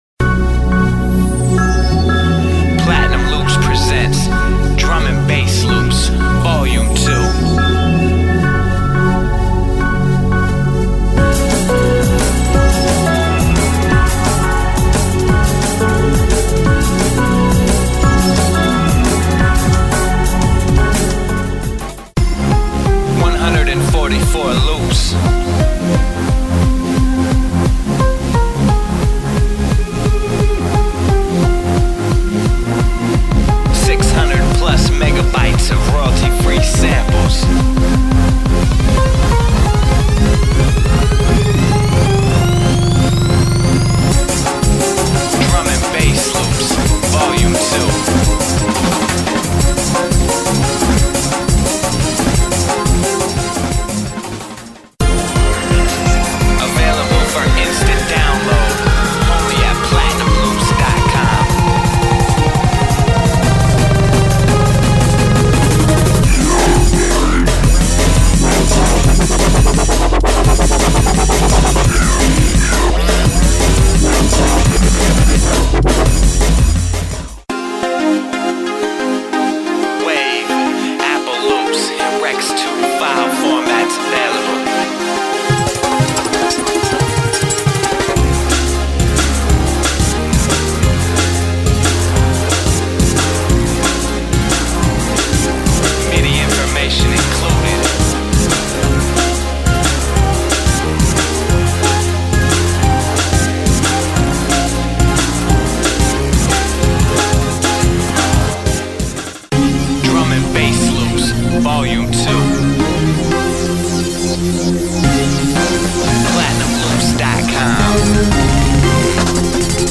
Download Loops and Samples 165 to 180 Bpm